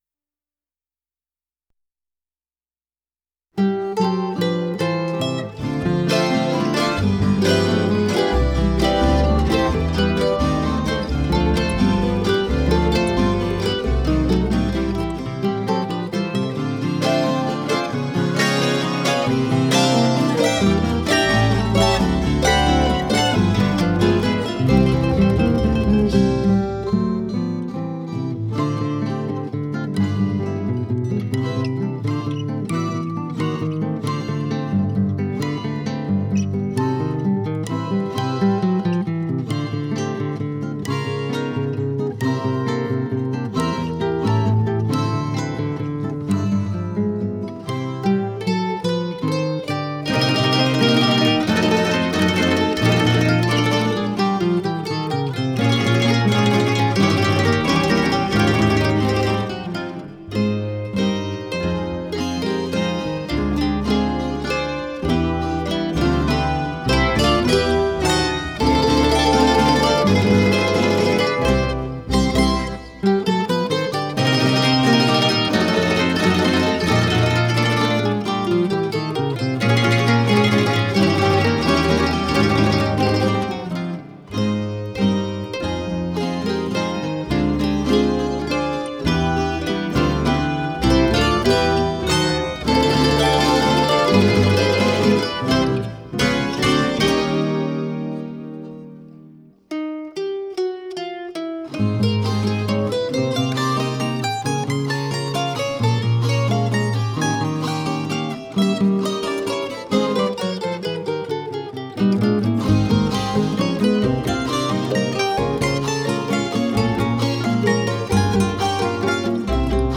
Pasillo .wav